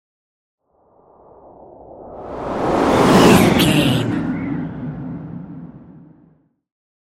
Whoosh airy long
Sound Effects
bouncy
futuristic
tension